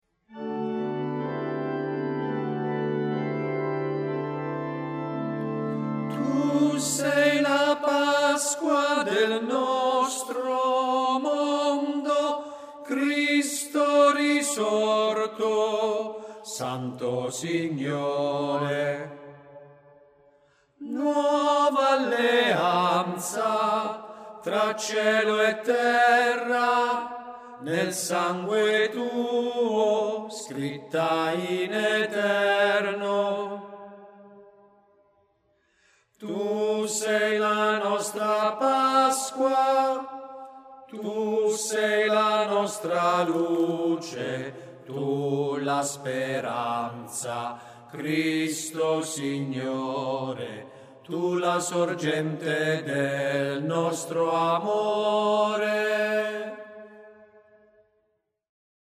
03tenori.MP3